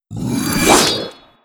Pigman_Swing.wav